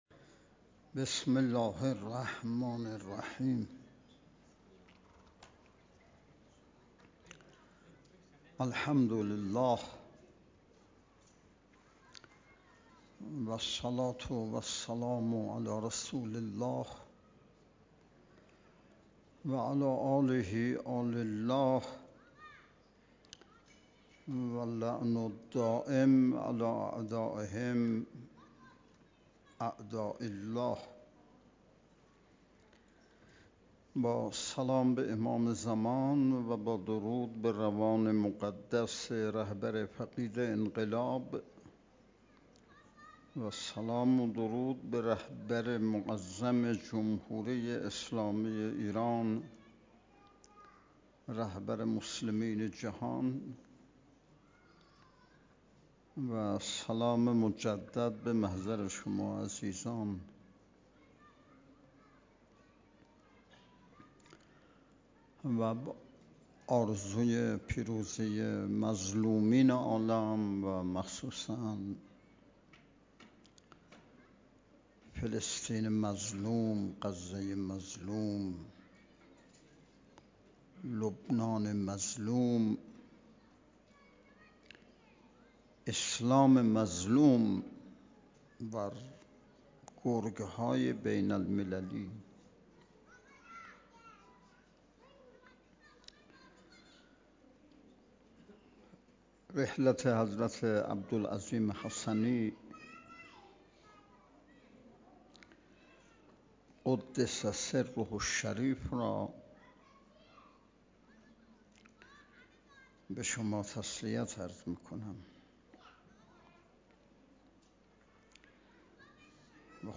صوت کامل بیانات حجت الاسلام و المسلمین سید علیرضا عبادی در ششمین جلسه جهادگران تبیین که امروز(۲۴فروردین ماه) با حضور فعالان فرهنگی، اجتماعی شهرستان بیرجند در محل حسینیه مسجد امام حسین علیه السلام برگزار شد،جهت بهره برداری منتشر گردید.